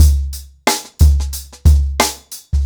TheStakeHouse-90BPM.37.wav